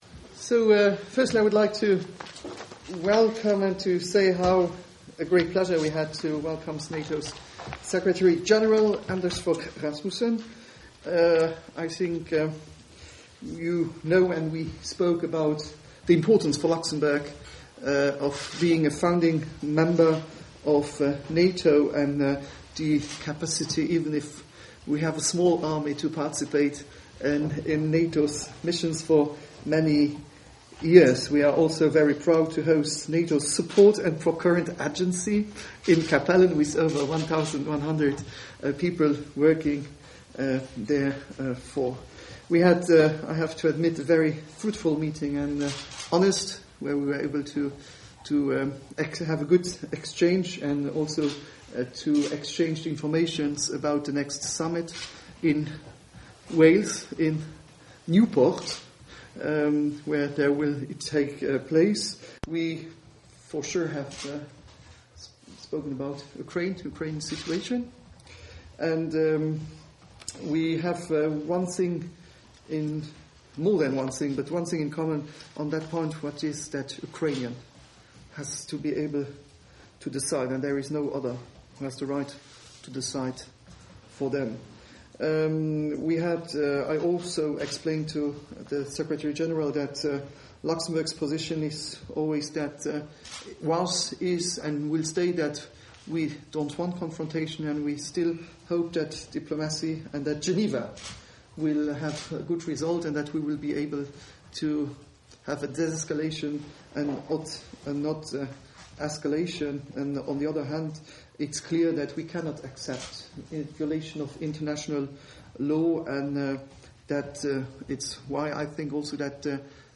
Doorstep statement by NATO Secretary General Anders Fogh Rasmussen at the meeting of the European Union Foreign Affairs Council at the level of Defence Ministers in Luxembourg 15.04.2014 | download mp3